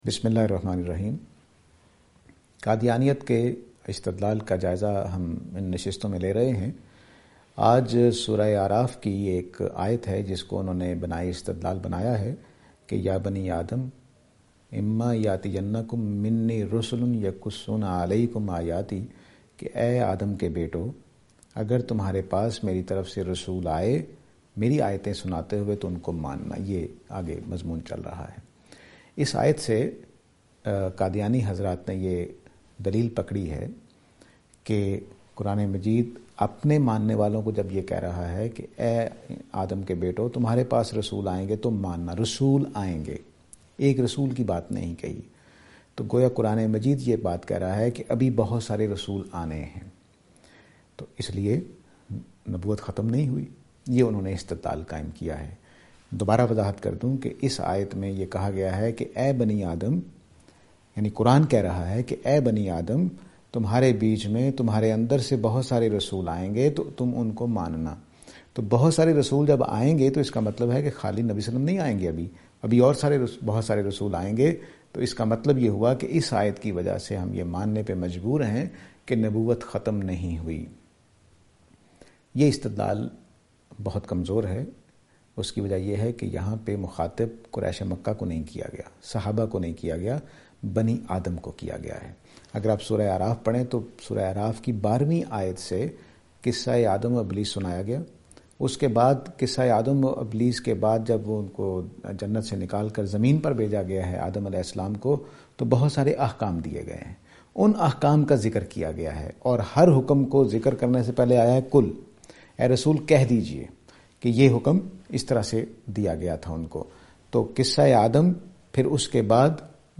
This lecture series will deal with Reviewing Qadiyani Discourse .